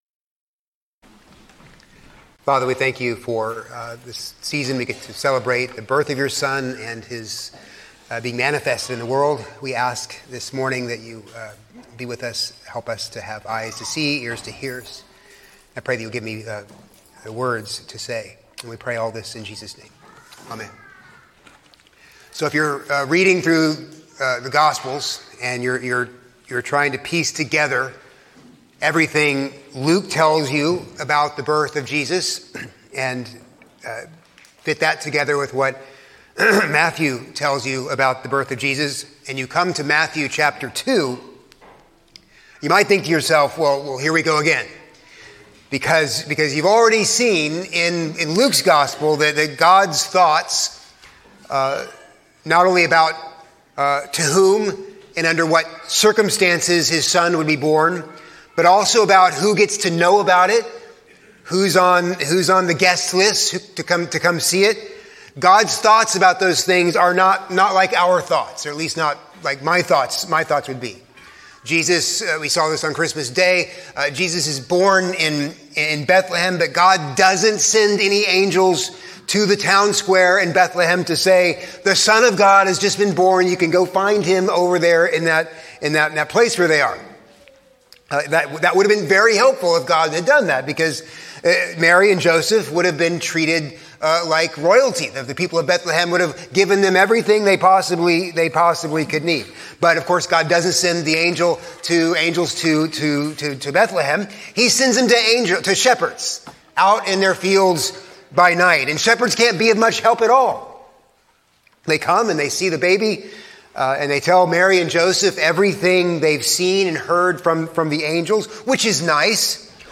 A sermon on Matthew 2:1-12